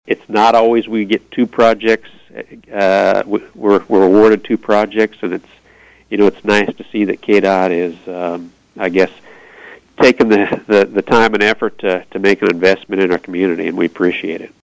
On KVOE’s Morning Show on Friday